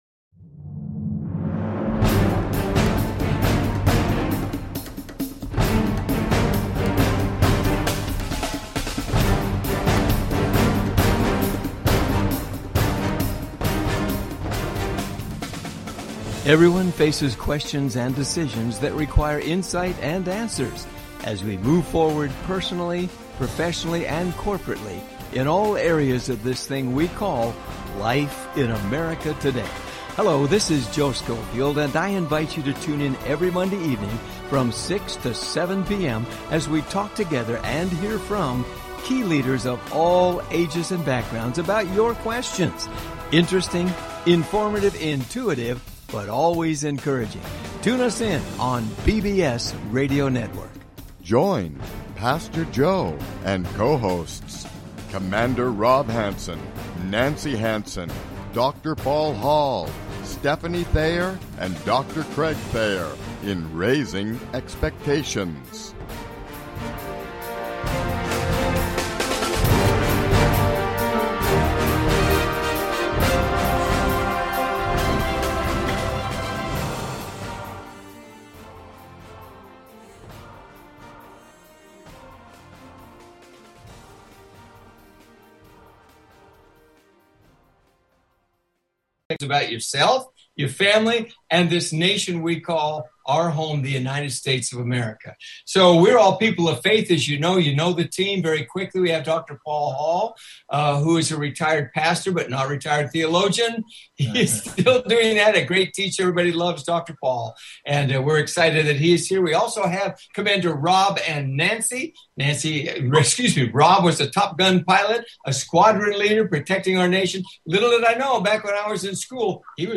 Talk Show Episode, Audio Podcast, Raising Expectations and Guest, Roy Douglas Wead, conservative commentator and writer.